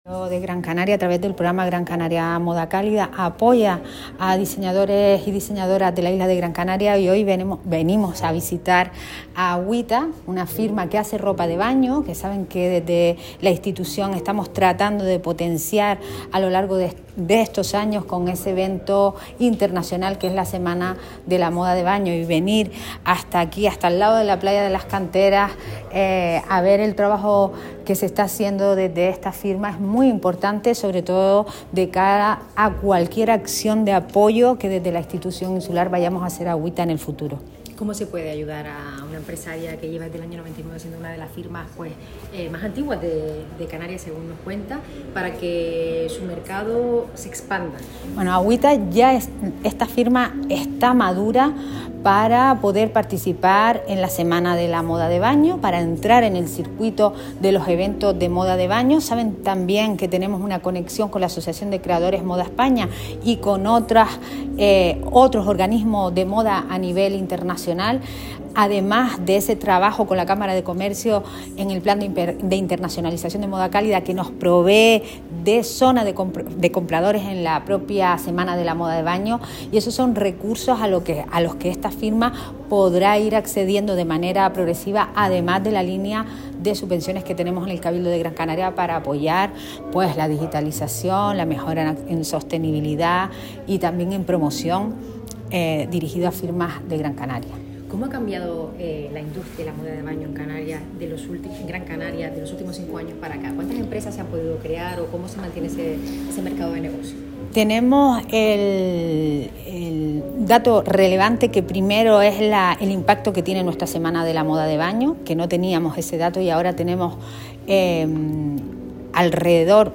Minerva-Alonso-consejera-de-Desarrollo-Economico-Cabildo-GC.m4a